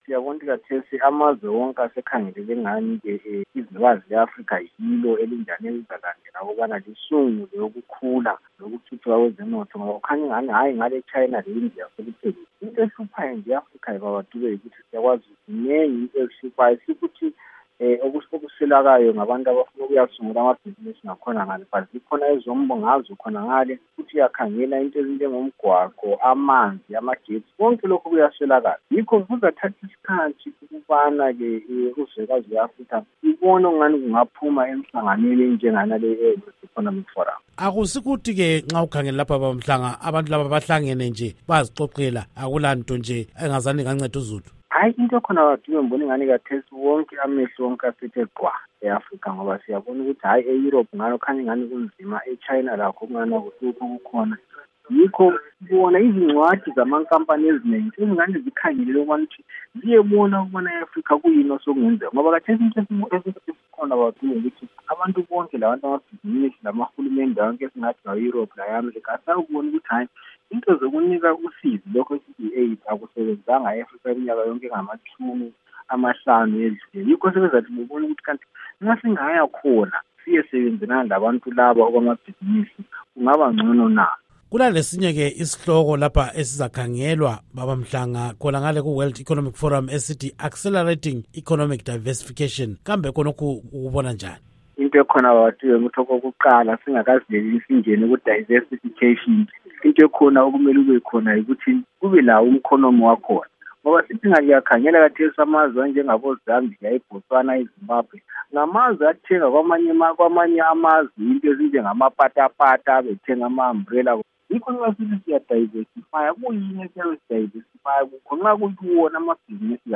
Ingxoxo Esiyenze LoMnu.